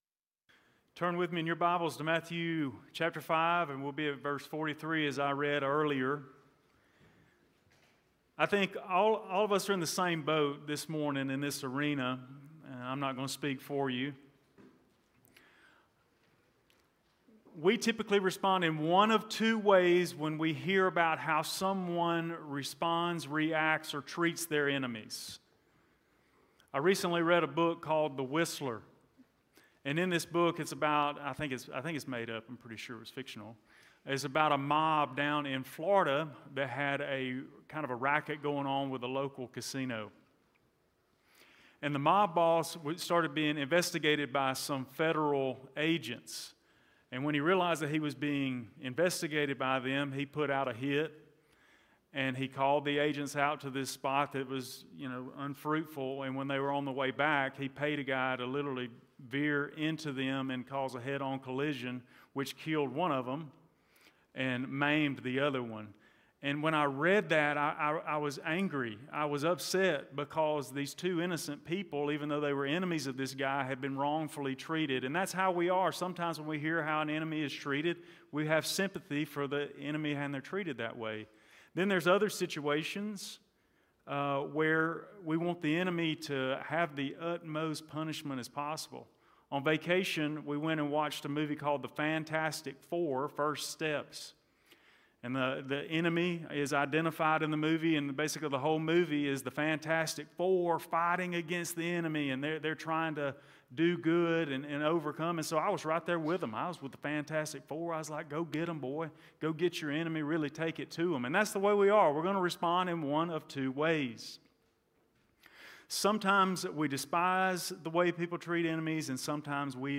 Blount Springs Baptist Church Sermons Behind Enemy Lines: Now What?